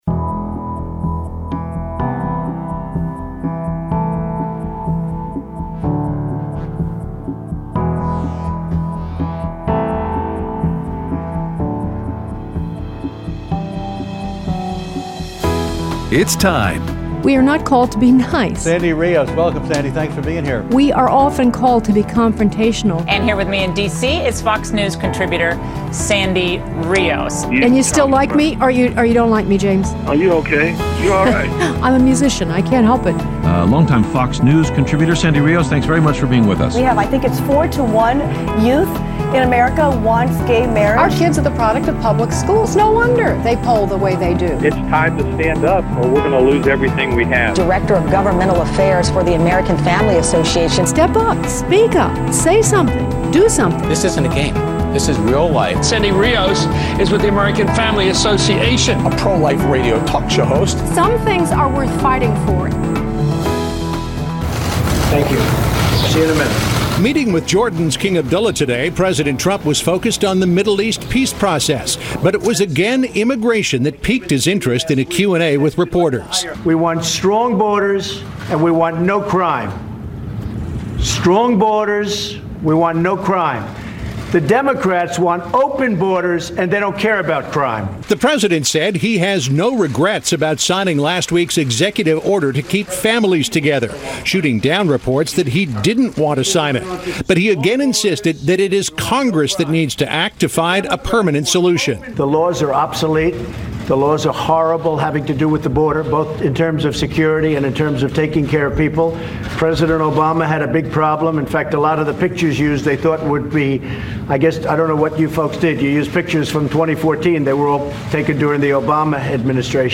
Several States Holding Primary Elections Today, Heckling and Harassing Conservatives, and Your Phone Calls
Aired Tuesday 6/26/18 on AFR 7:05AM - 8:00AM CST